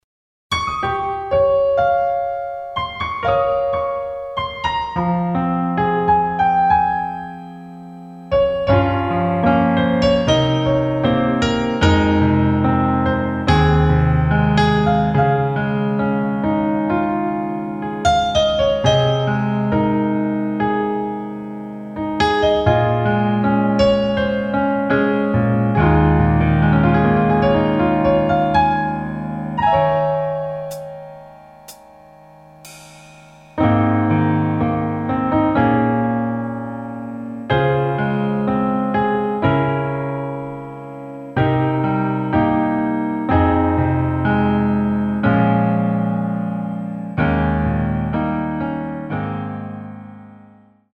피아노 반주만으로 리메이크한 곡
키 Ab
원곡의 보컬 목소리를 MR에 약하게 넣어서 제작한 MR이며